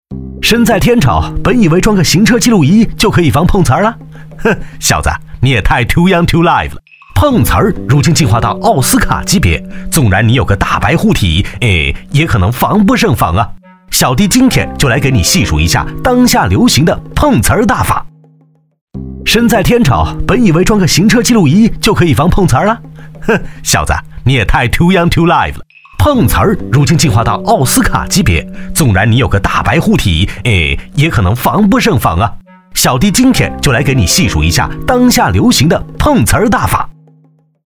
国语青年大气浑厚磁性 、沉稳 、男专题片 、宣传片 、120元/分钟男S347 国语 男声 专题片-三国解说-成熟抒情 大气浑厚磁性|沉稳